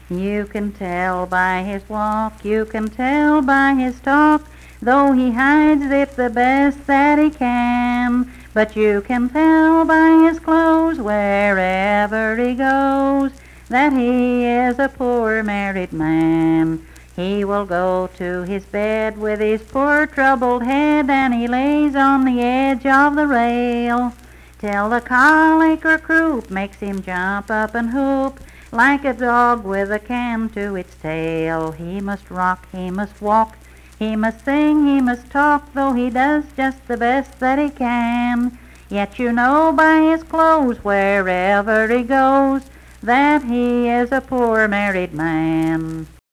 Unaccompanied vocal music performance
Verse-refrain 1(12).
Voice (sung)